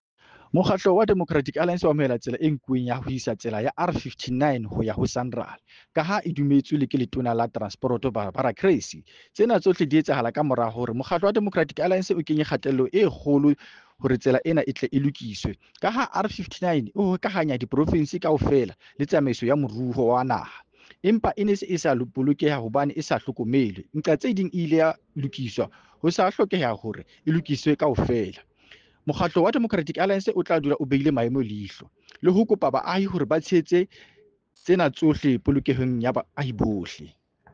Sesotho soundbite by Cllr Joseph Mbele.